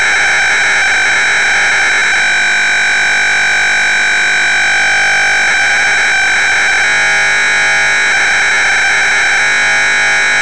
The beacon is very strong about 20-30db over the noise and solid thought out the pass.
2 Meter Telemetry